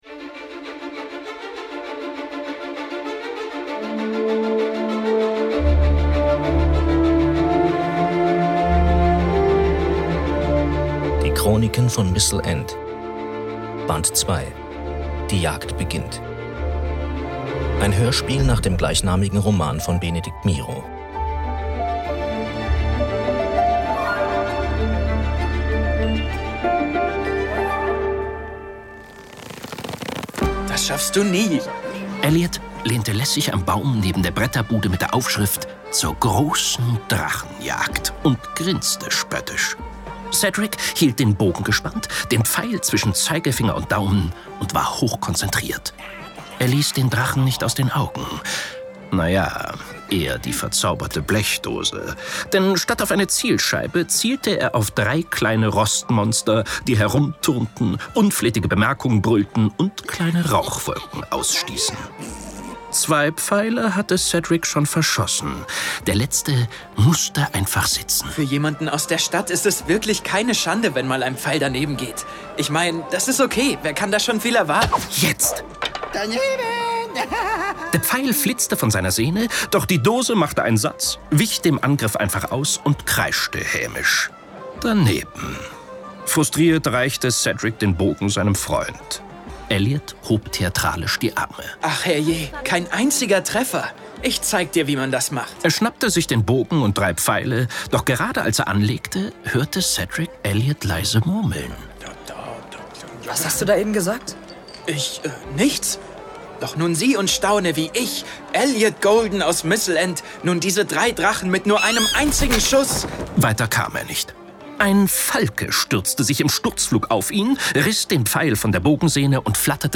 Die Chroniken von Mistle End – Teil 2: Die Jagd beginnt Hörspiel